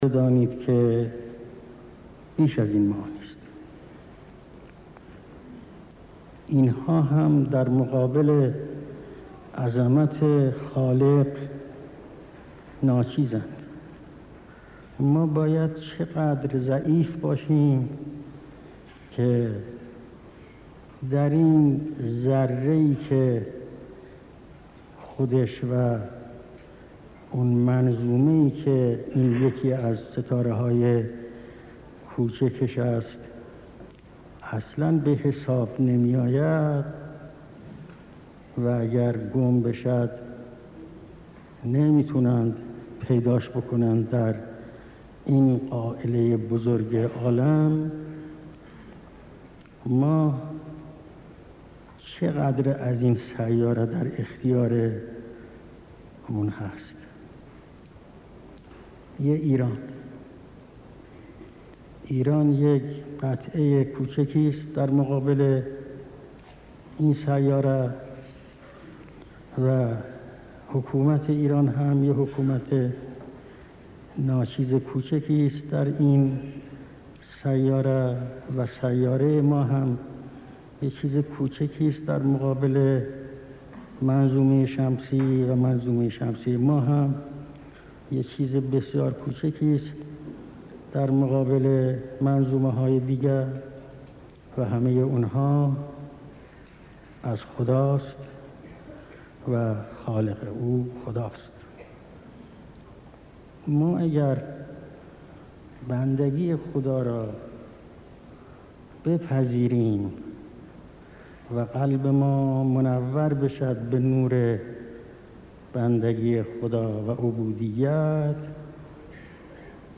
سخنرانى در مراسم تنفيذ حكم رياست جمهورى آقاى رجايى (نصيحت به مسئولان)